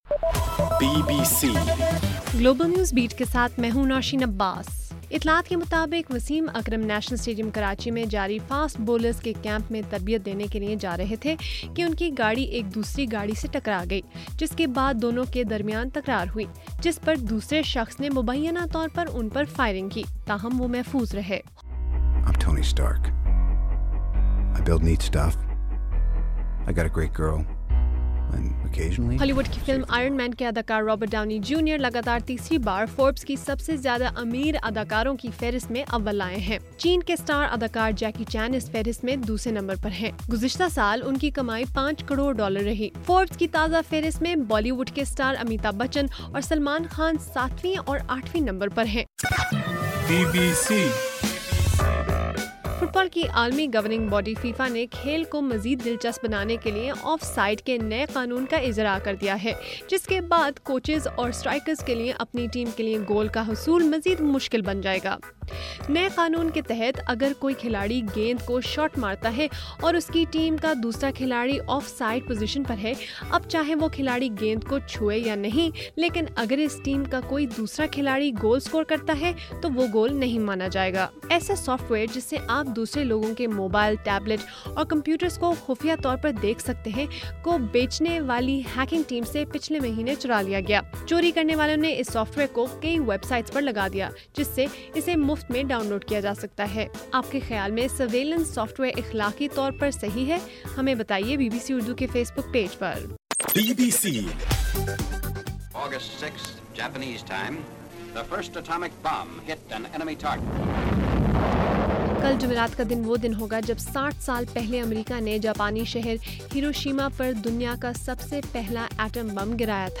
اگست 5: رات 12 بجے کا گلوبل نیوز بیٹ بُلیٹن